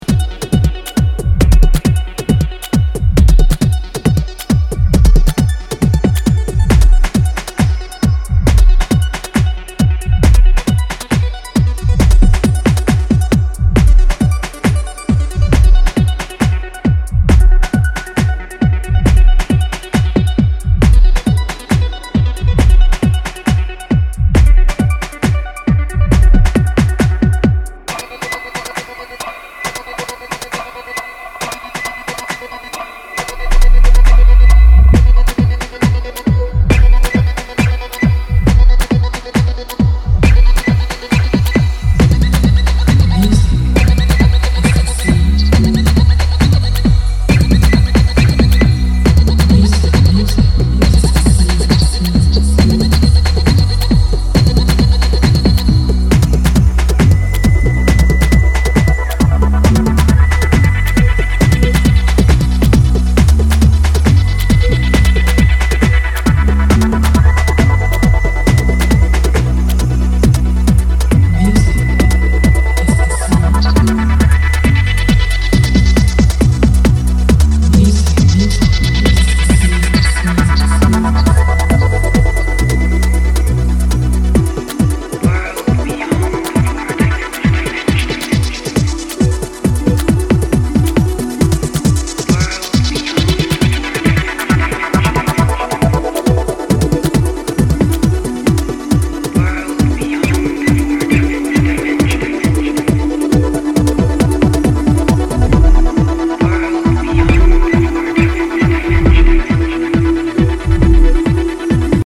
跳ねる136BPMバンガー
全編に渡り陽性でポジティブなイメージを伝えてくるメロディーセンスが好ましい。